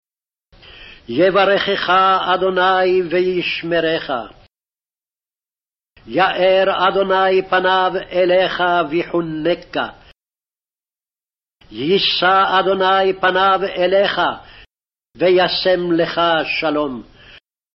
Original Speed with extra time between phrases |